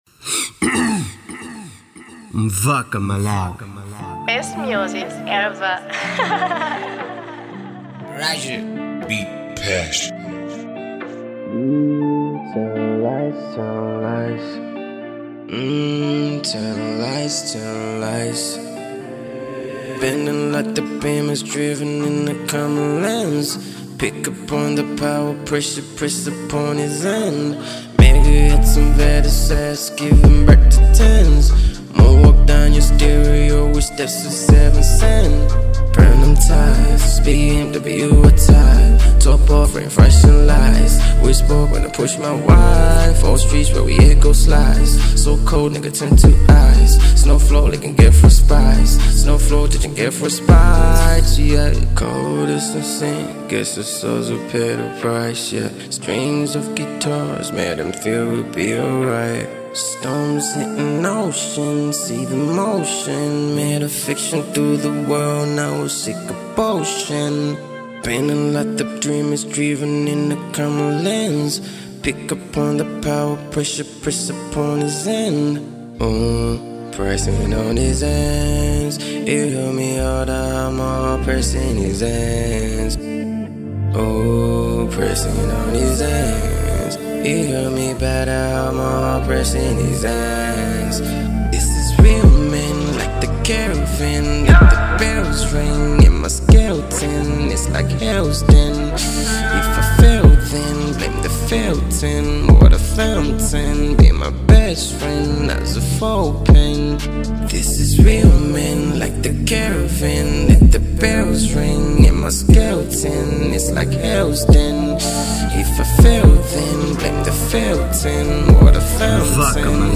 type: SOUL